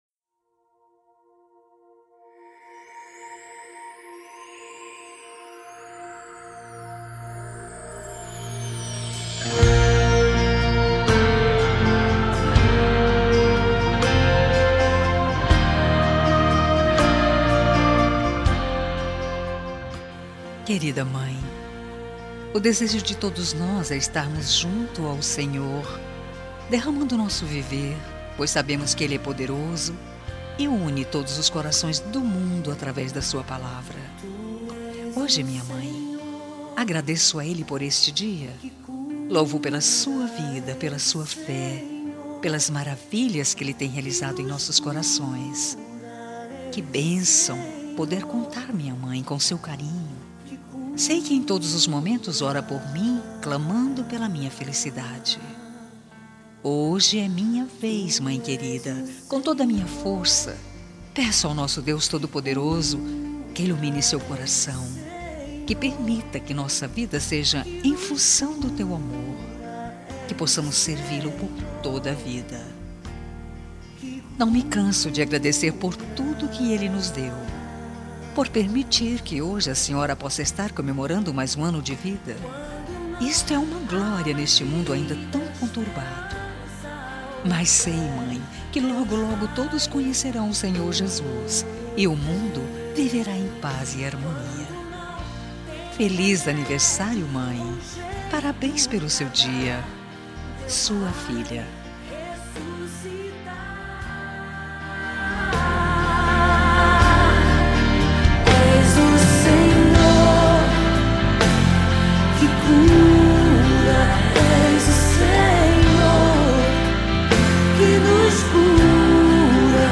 Telemensagem Evangélica Anversário Mãe | Com Reação e Recado Grátis